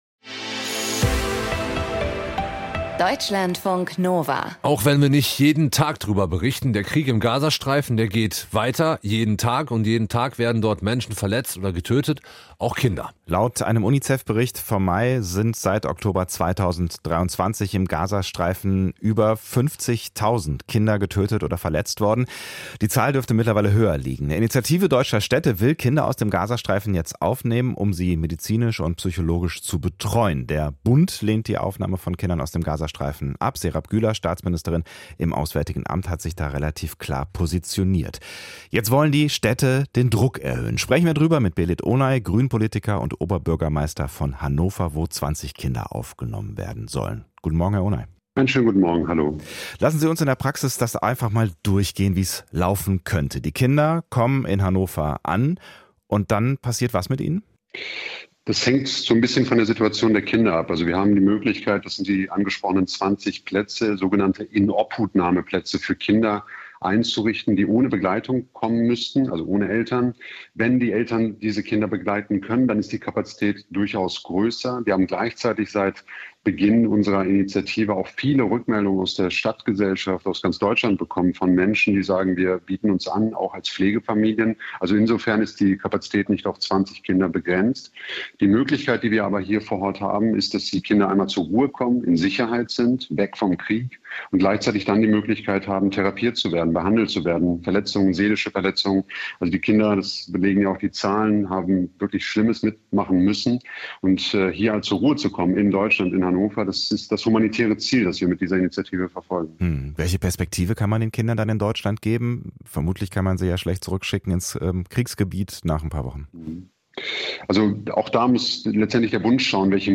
Ein Gespräch über Nahost, Schuld, und eine Freundschaft, die zu zerbrechen droht.